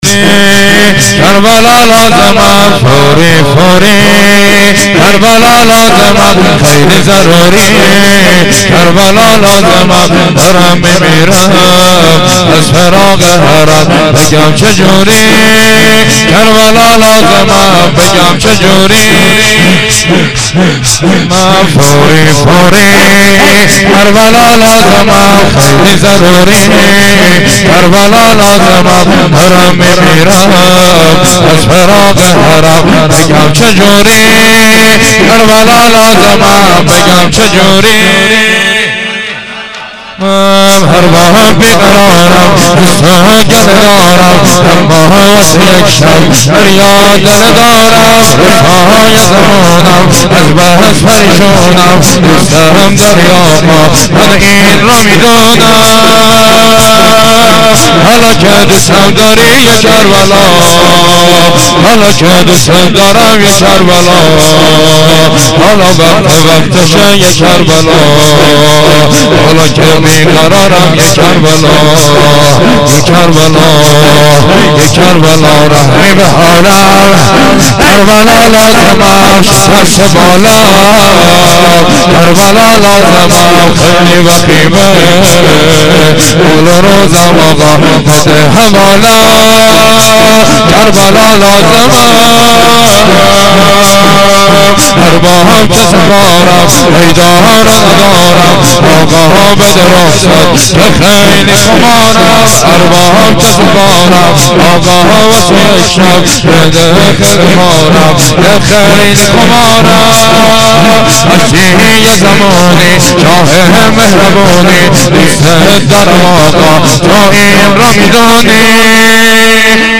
فایل های صوتی مراسم هفتگی مرداد 94
haftegi-22-mordad-94-shor1.mp3